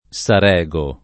Sarego [ S ar $g o ]